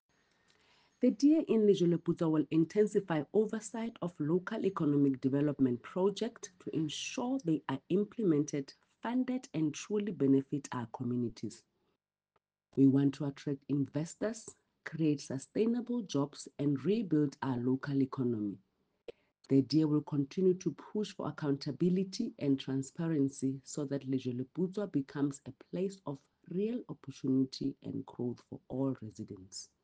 Sesotho soundbites by Cllr Mahalia Kose.